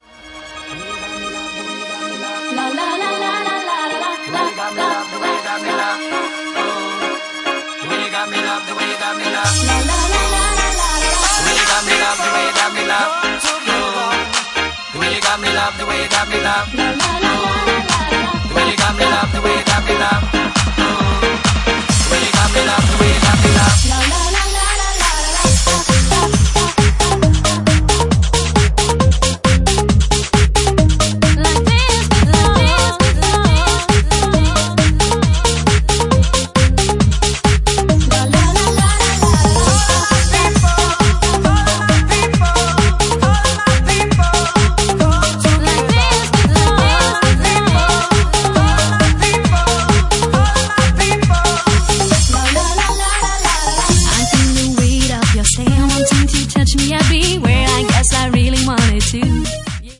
Genre:Bassline House
Bassline House at 136 bpm